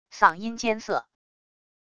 嗓音艰涩wav音频